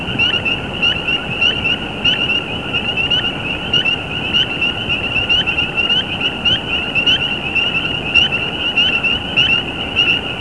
Northern Spring Peeper
Voice- a short high, uprising "
peep." (wav file 224Kb)
Males call from an exposed perch and can be located with a flashlight by slowly zeroing in on the call of a single male.
peepers.wav